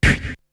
Beatbox 11.wav